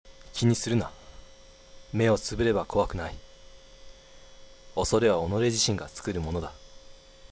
１８歳／男
■　Voice　■